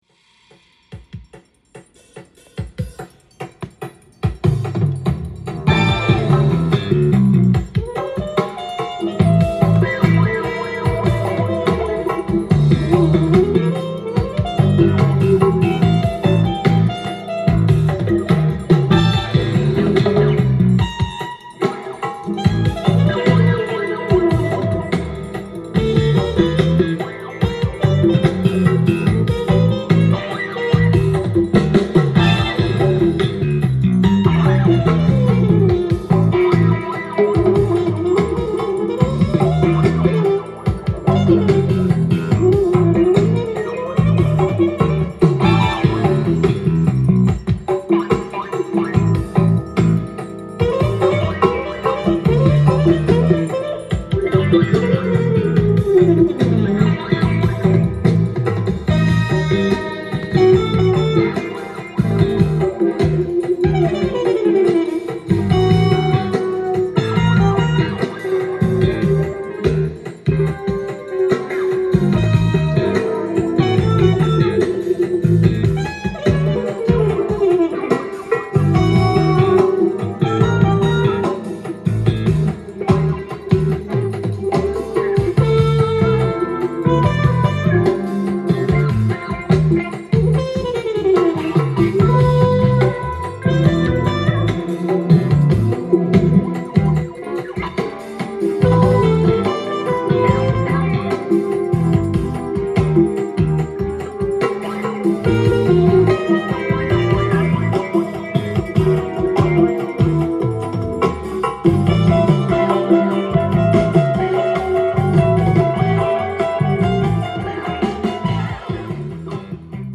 ジャンル：FUSION
店頭で録音した音源の為、多少の外部音や音質の悪さはございますが、サンプルとしてご視聴ください。
スピリチュアル・ジャズ・フュージョン